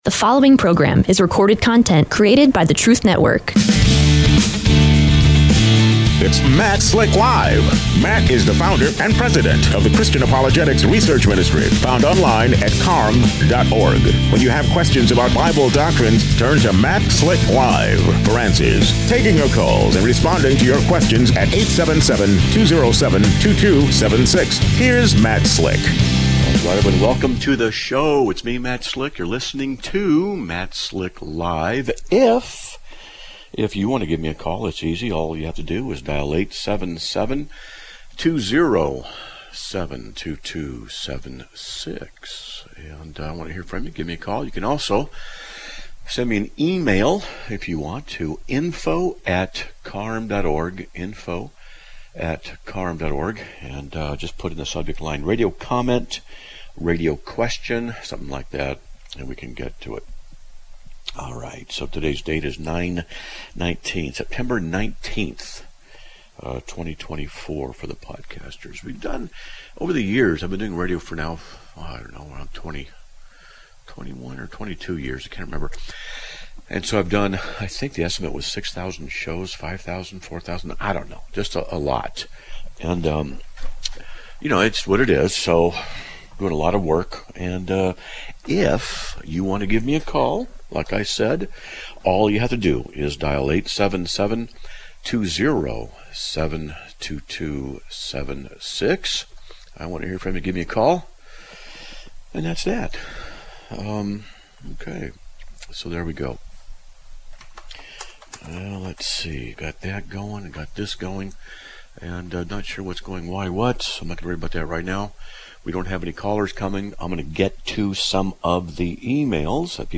answers questions on topics such as: The Bible, Apologetics, Theology, World Religions, Atheism, and other issues!